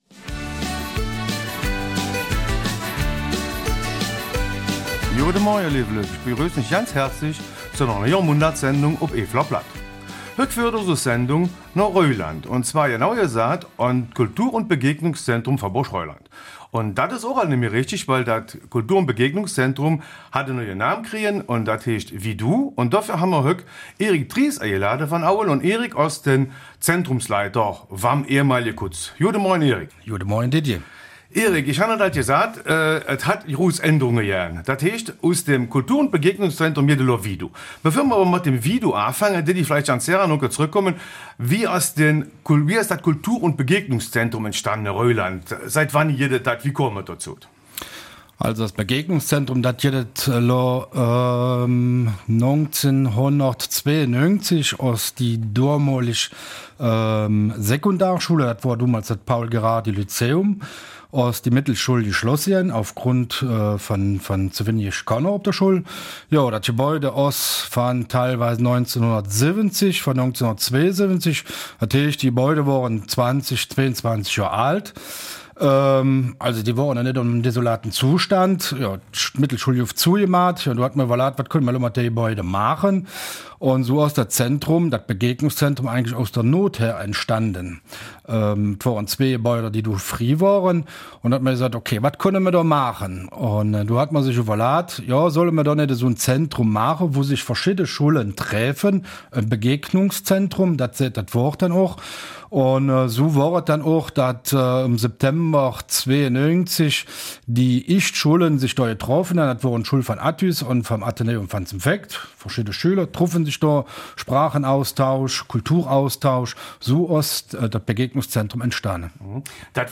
Eifeler Mundart: Aus dem Kultur- und Begegnungszentrum wird ViDo.